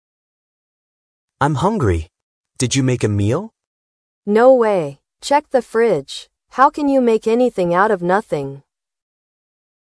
空所に発音しながら国の名前を入れて行けば答えは出ると思いますが，　ダジャレ抜きの本来の英語はどうなのか音声でお伝えします。